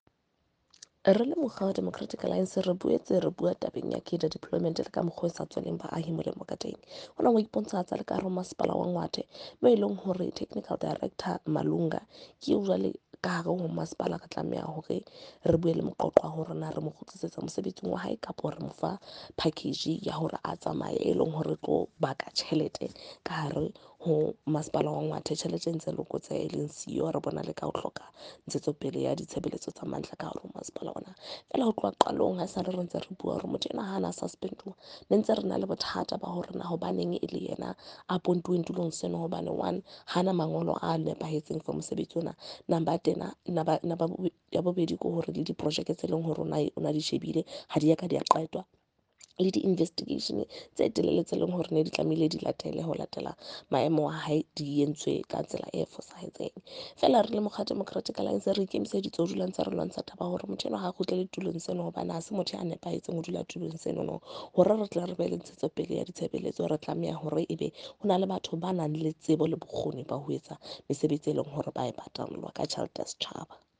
Afrikaans soundbites by Cllr Carina Serfontein and
Sesotho by Karabo Khakhau MP.